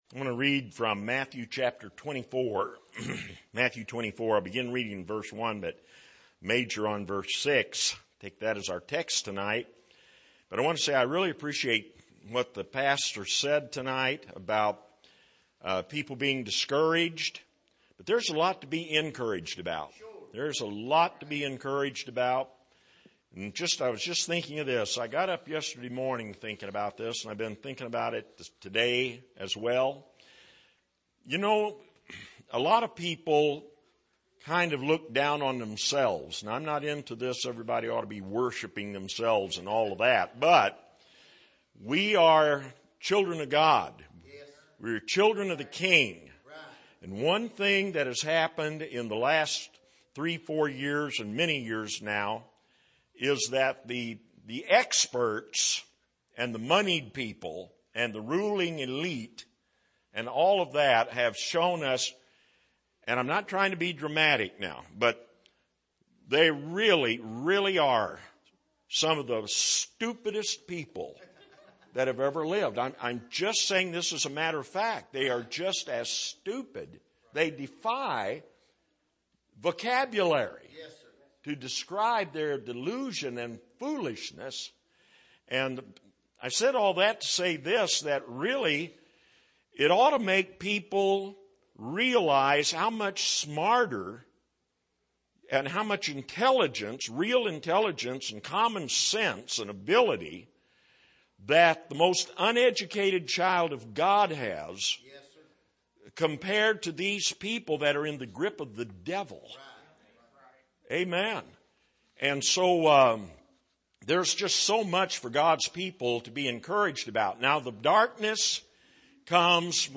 Passage: Mathew 24:1-6 Service: Sunday Evening